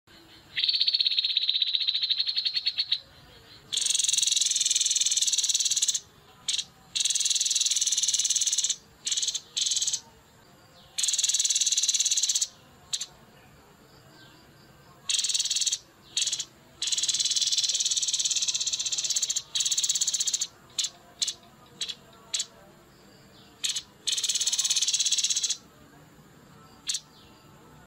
MASTERAN VIRAL TEPUS KEPALA ABU